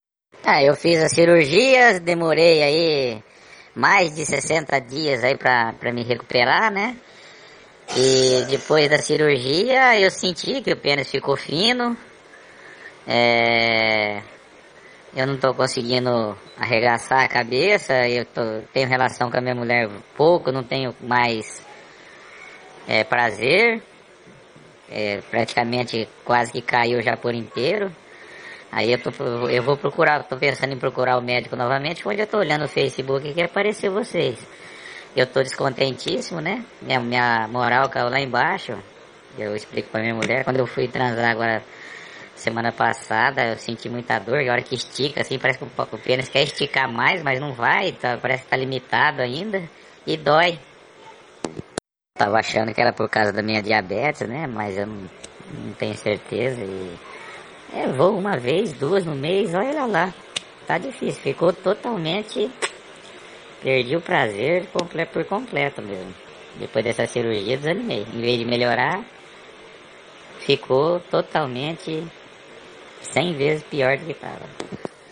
Ouça agora também um depoimento de um homem que fez a cirurgia de freio curto e hoje teve a sua vida arruinada definitivamente!
voz-mudada.wav